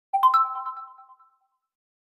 Realme Notification Efecto de Sonido Descargar
Realme Notification Botón de Sonido